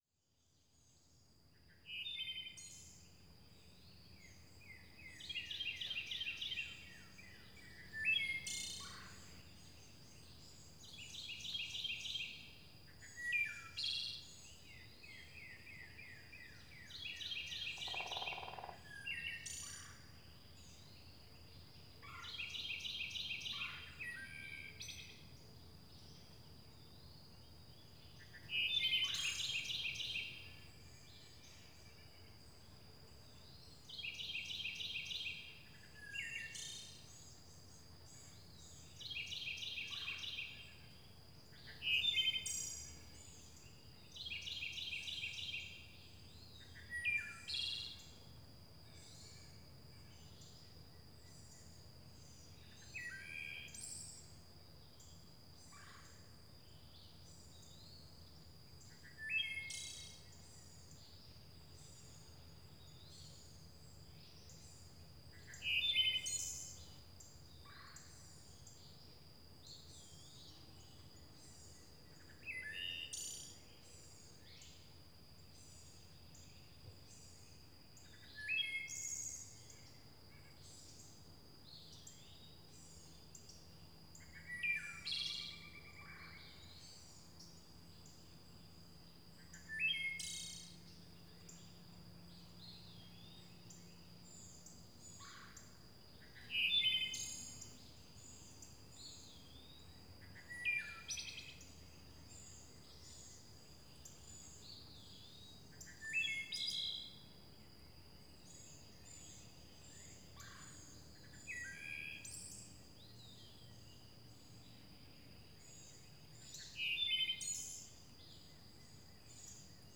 forest.wav